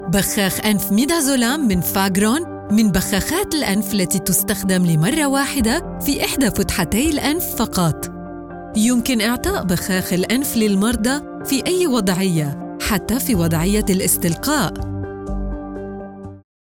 Demo comercial
Narración
Soy locutora árabe a tiempo completo y trabajo desde mi propio estudio de alta calidad en Beirut, Líbano.
Adulto joven
Mediana edad